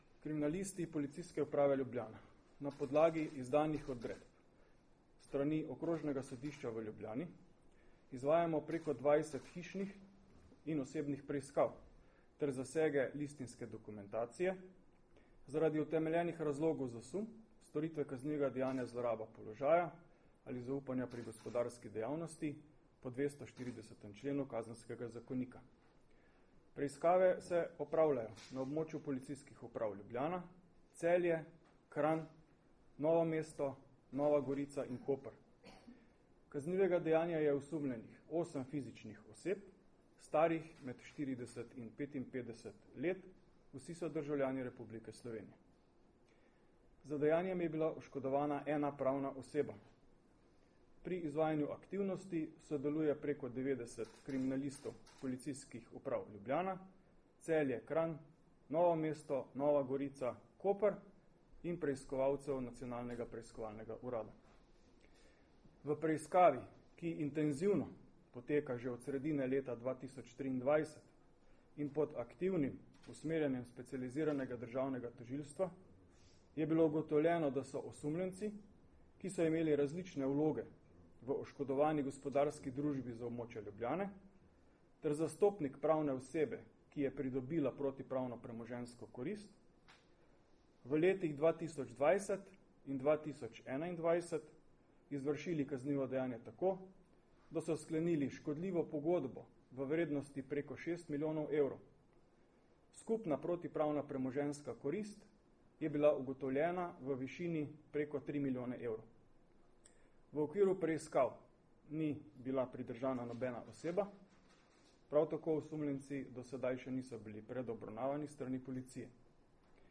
Glede današnjih operativnih aktivnosti, ki smo jih v zvezi s kaznivim dejanjem s področja gospodarske kriminalitete izvajali na območju več policijskih uprav, sta predstavnika Policije danes, 29. maja 2024, na Generalni policijski upravi podala kratko izjavo za javnost.